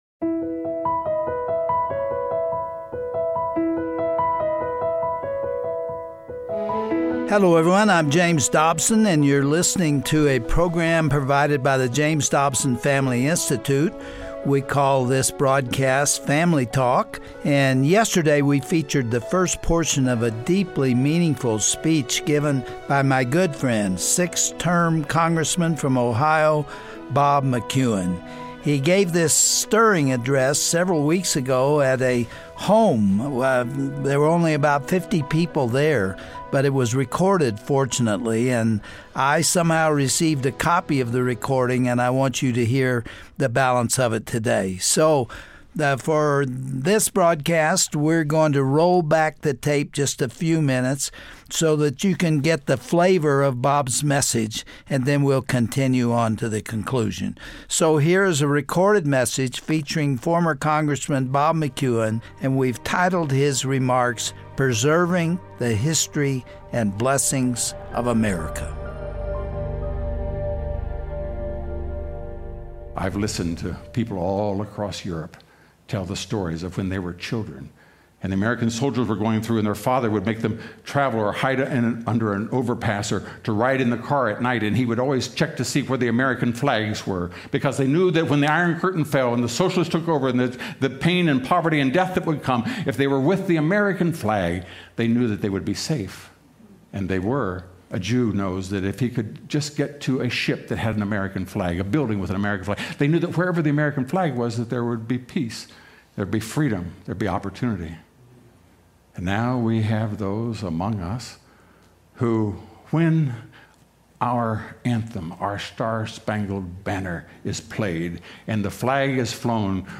Former Congressman Bob McEwen reminds us why we must honor and protect our nation's Christian legacy. Also featured is a recorded message from Dr. James Dobson at a prayer event in Washington D.C., with a call for America to return to its Judeo-Christian roots.